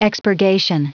Prononciation du mot expurgation en anglais (fichier audio)
Prononciation du mot : expurgation